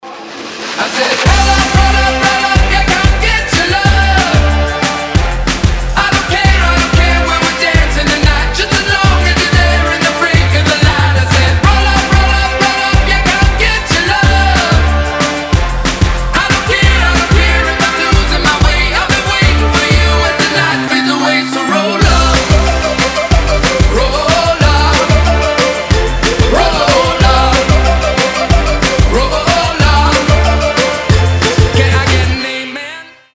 заводные
indie pop
alternative
Бодрый саундтрек